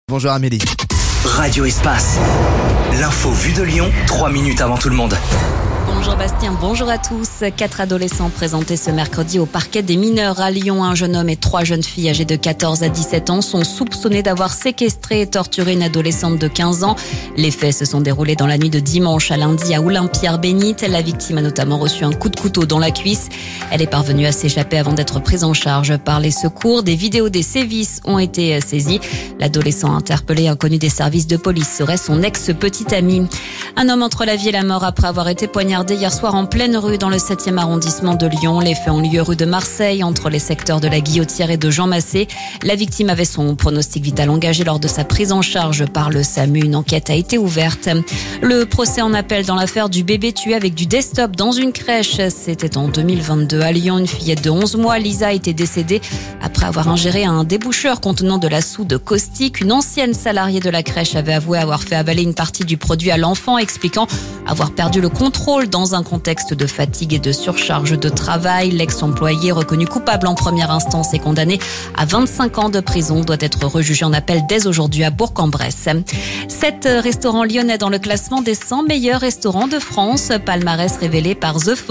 Flash info